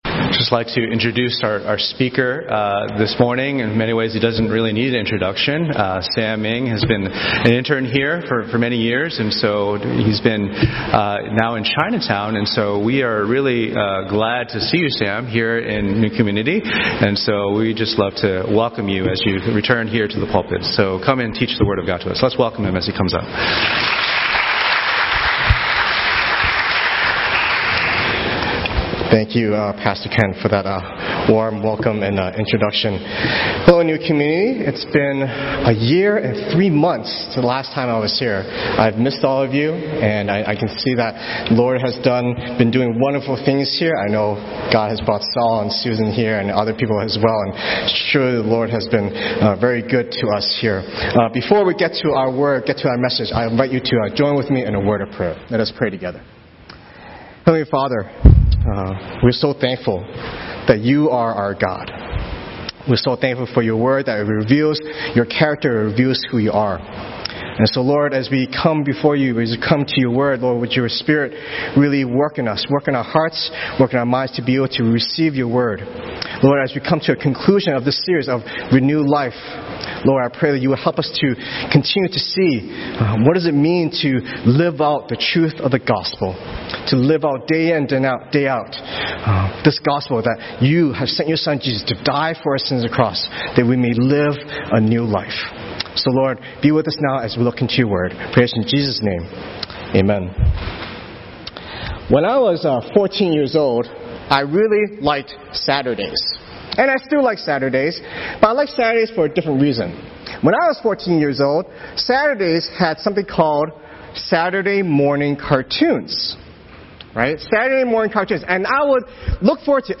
The umbrella term/category for all Sermons from all congregations.